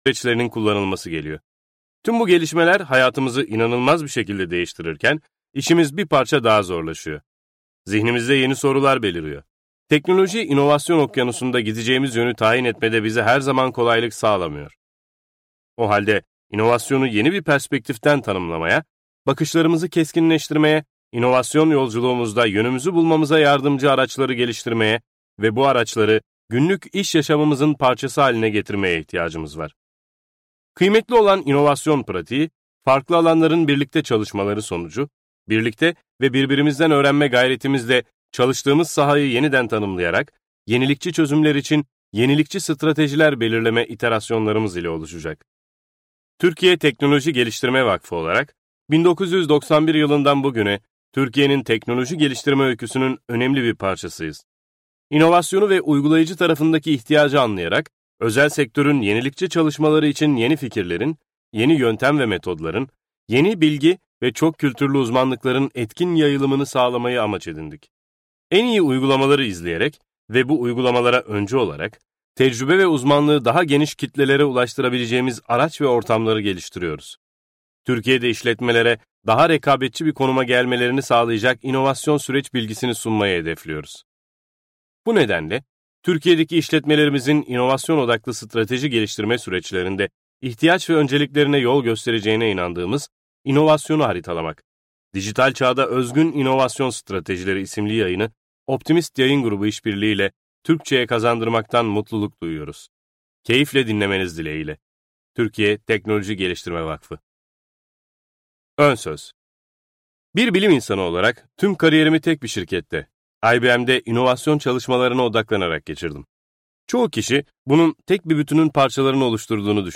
İnovasyonu Haritalamak - Seslenen Kitap